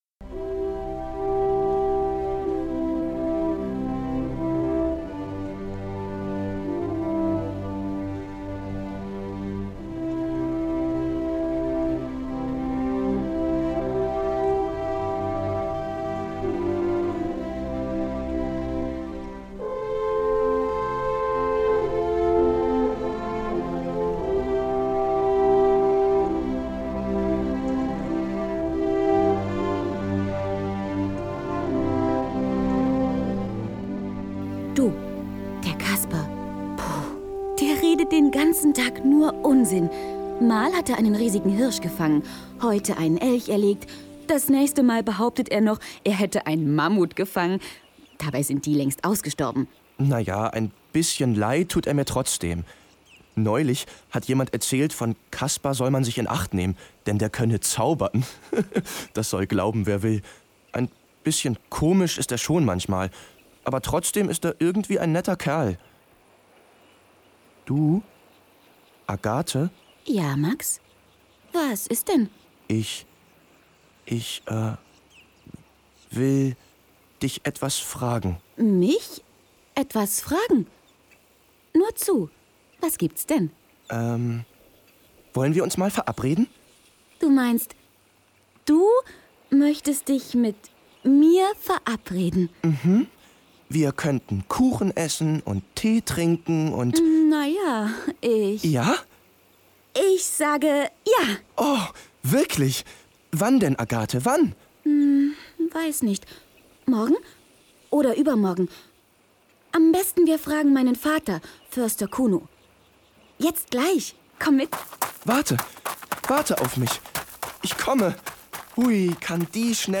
Hörspiel mit Opernmusik